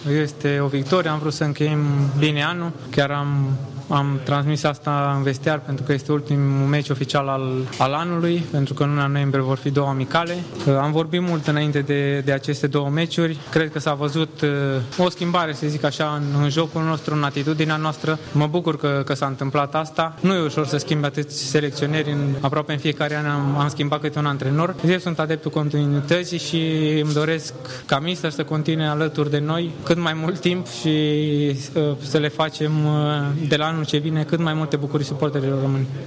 Albaiulianul Nicolae Stanciu a declarat că îşi doreşte ca Edi Iordănescu să rămână selecţioner.